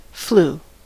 Ääntäminen
UK : IPA : /fluː/